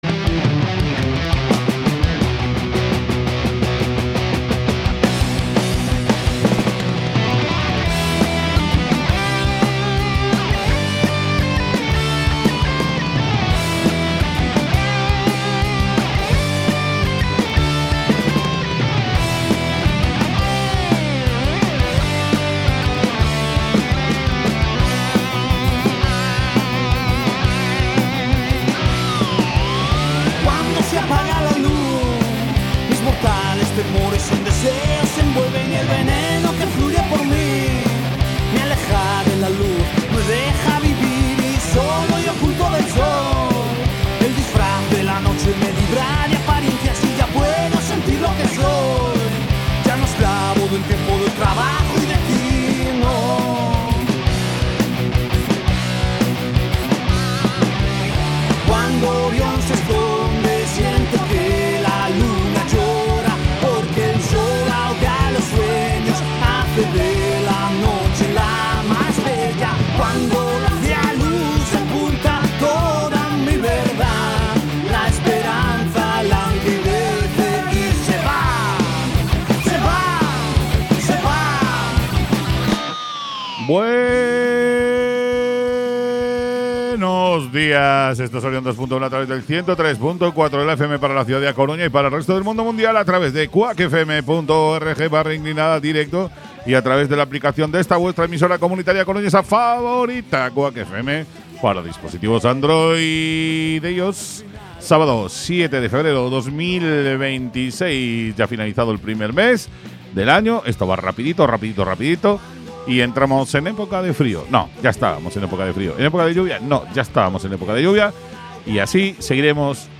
Programa de Rock y heavy en todas sus vertientes con un amplio apartado de agenda, de conciertos y eventos, en la ciudad y Galicia. Entrevistas, principalmente a bandas gallegas, y repleto de novedades discográficas.